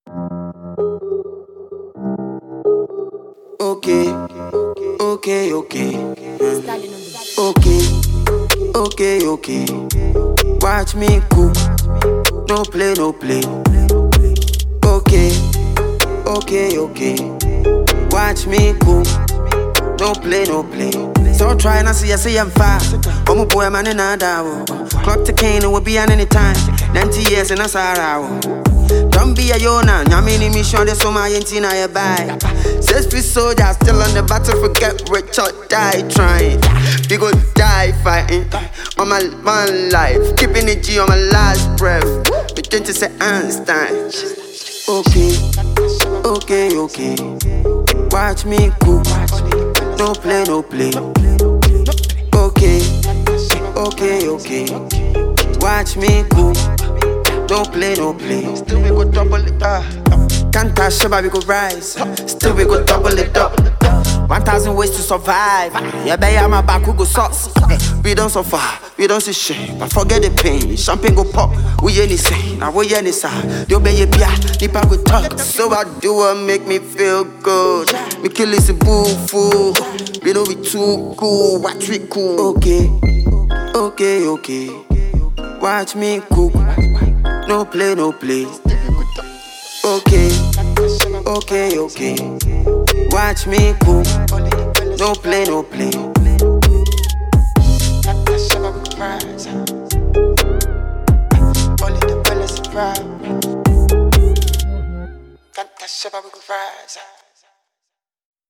serious energy and intent
streetwise flow and clever punchlines